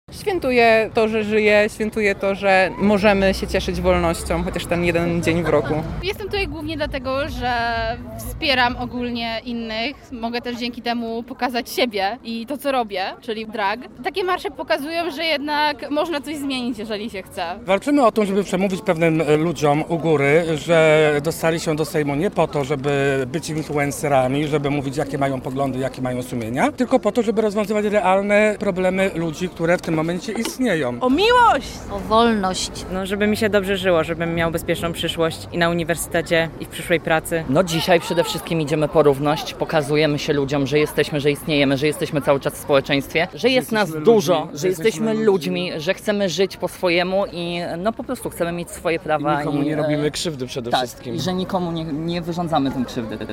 Pochodowi towarzyszyła muzyka i kolorowe stroje, a przeszły w nim setki osób walczących o prawo do równości.